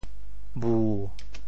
潮州府城POJ bū 国际音标 [bu]